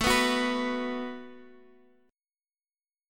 B5/A Chord